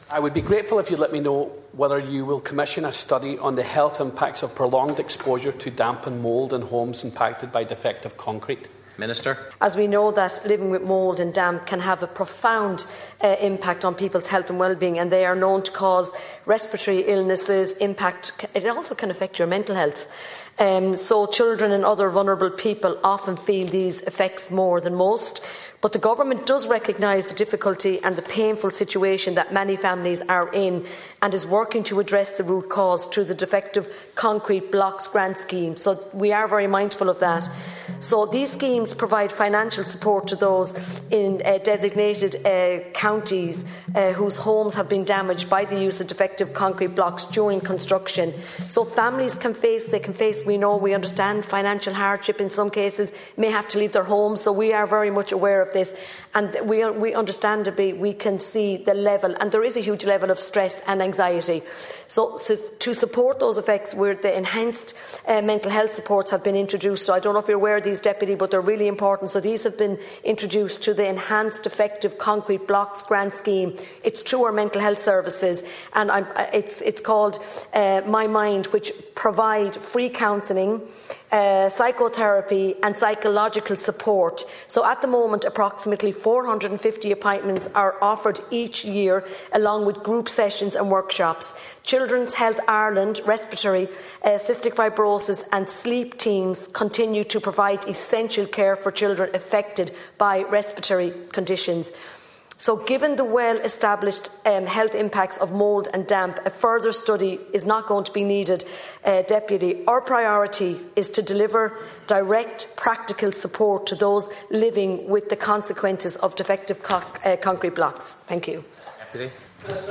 A Donegal TD has challenged the Government in the Dáil over the plight of seriously ill and terminally ill residents trapped in defective concrete homes.
You can listen to the full exchange between Deputy Ward and Minister Jennifer Murnane O’Connor here –